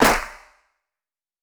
TC3Clap3.wav